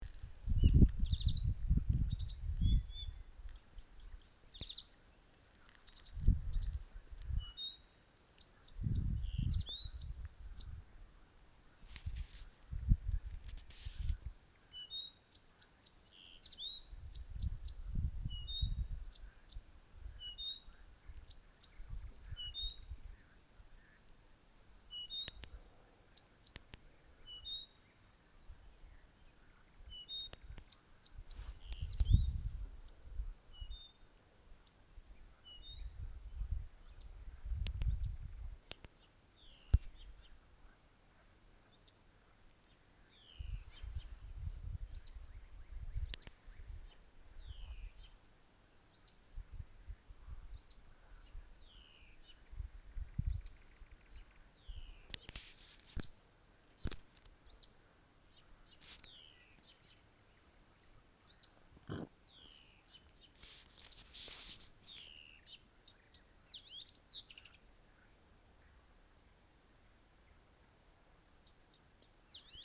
Blyth’s Reed Warbler, Acrocephalus dumetorum
Count2
StatusSinging male in breeding season
NotesDzied pa naktīm. Viens te, otrs metrus 200-300 pa labi gar ceļu